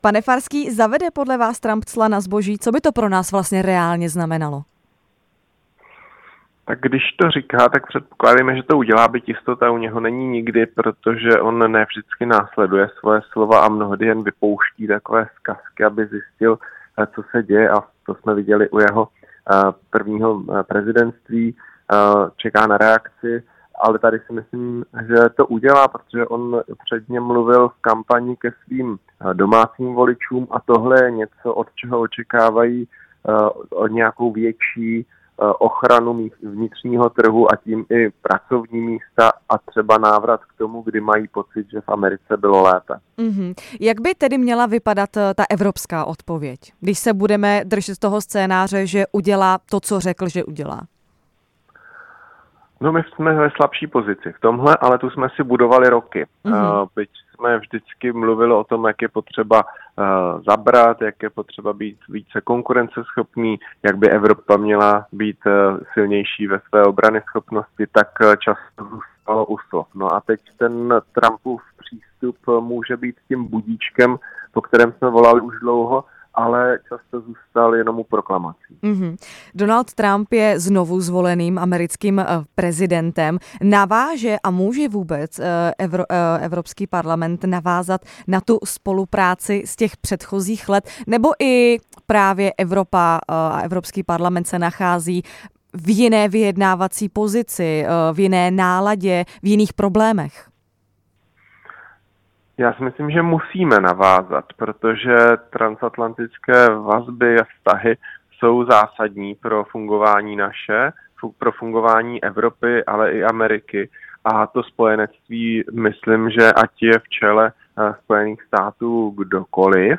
Hostem ranního vysílání Radia Prostor byl europoslanec Jan Farský z hnutí STAN.